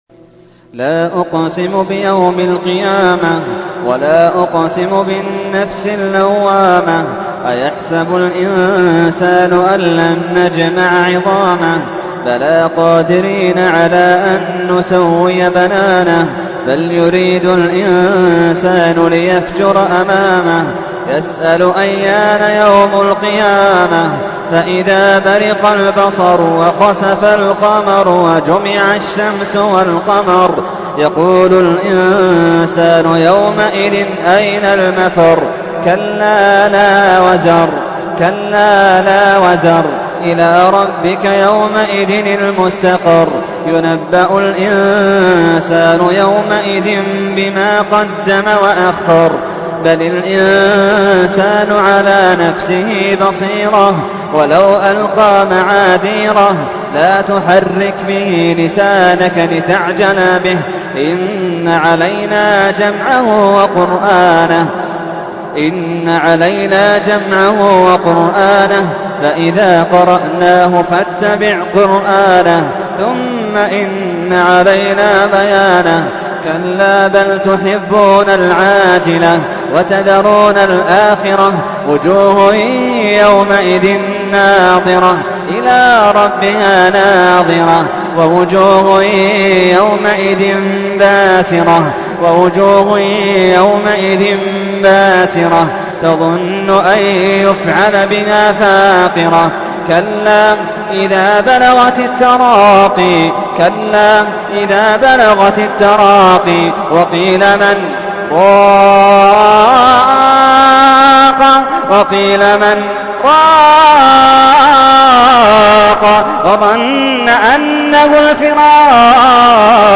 تحميل : 75. سورة القيامة / القارئ محمد اللحيدان / القرآن الكريم / موقع يا حسين